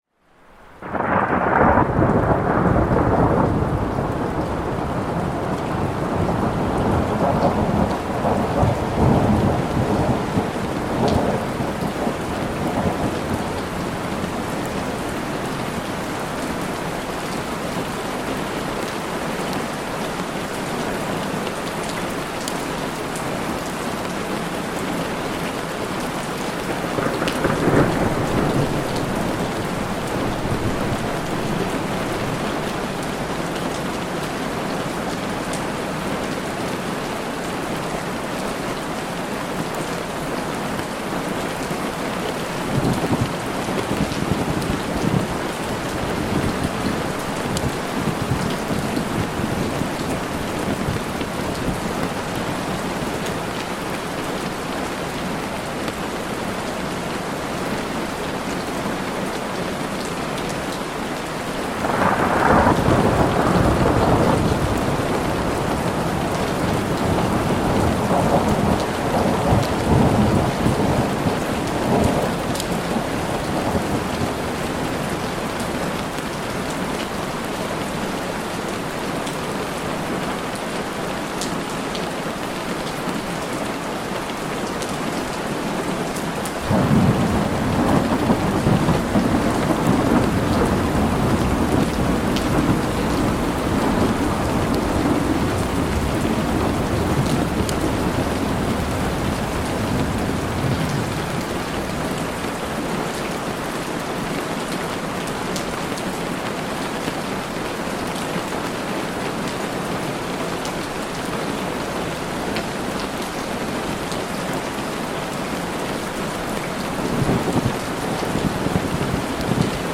Pluie de camping immersive pour un repos naturel au cœur de la nature